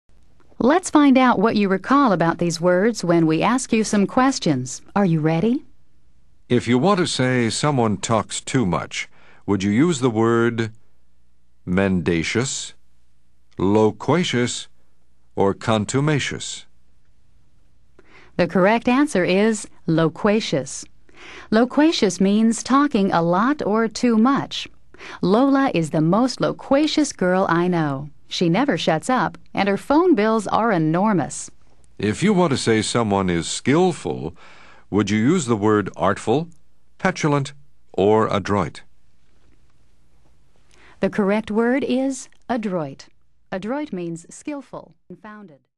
Learn 1000s of new words, each pronounced, spelled, defined, and used in a sentence.